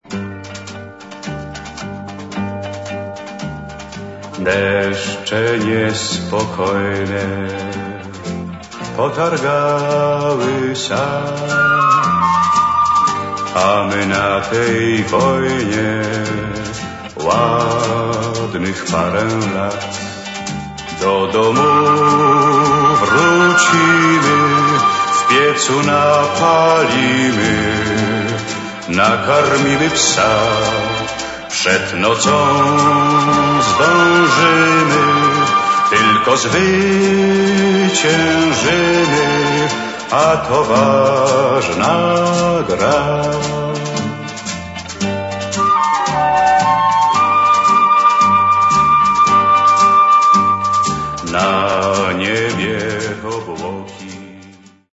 piosenka z serialu